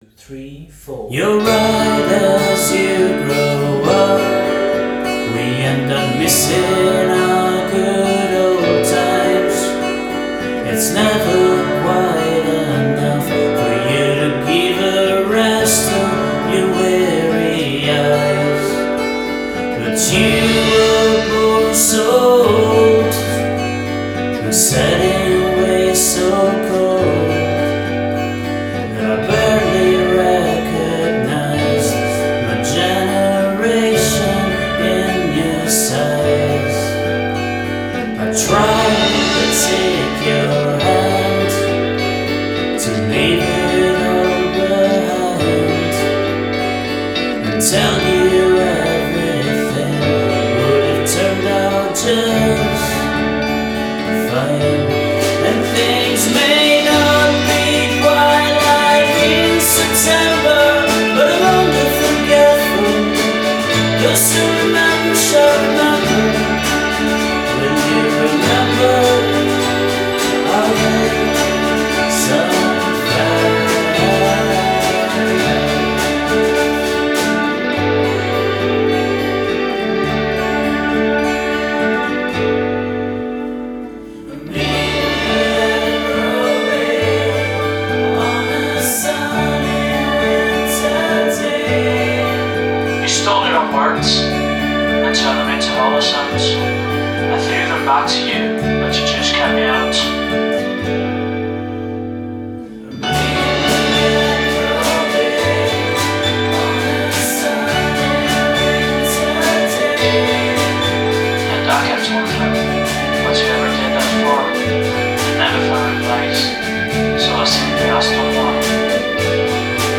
vocals, guitars, bass, drums, keyboards